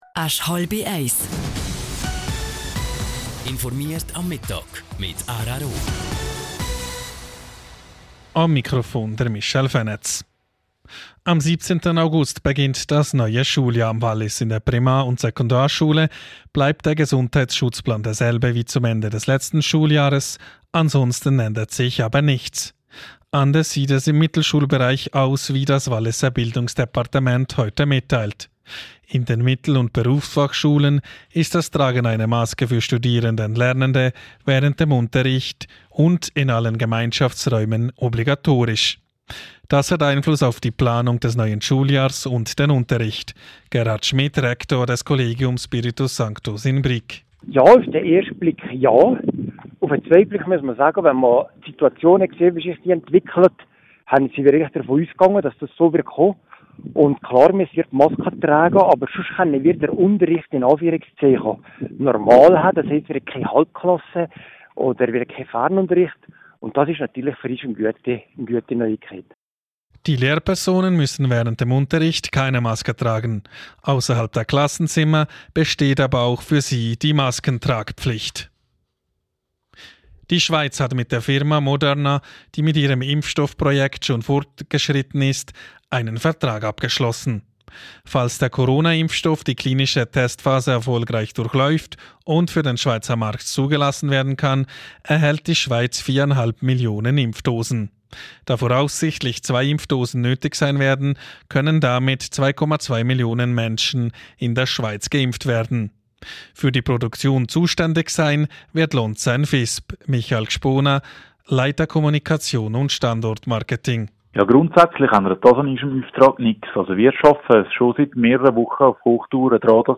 12:30 Uhr Nachrichten (4.1MB)